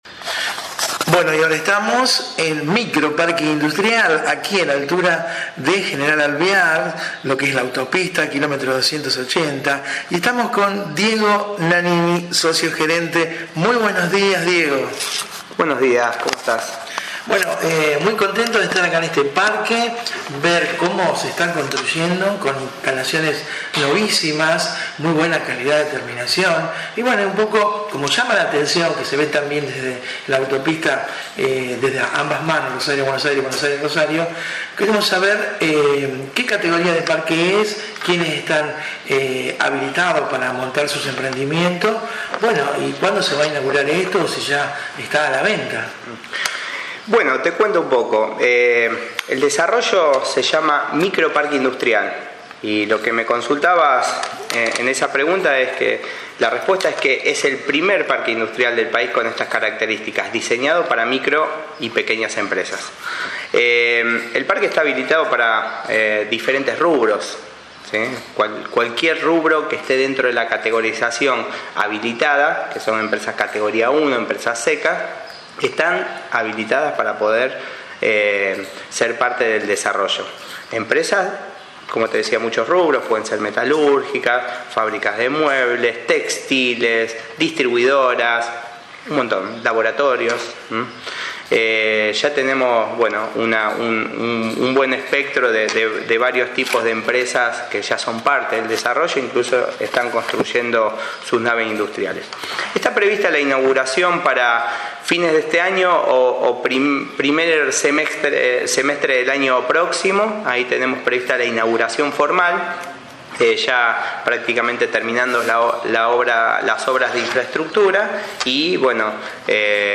En conferencia nos decía lo siguiente.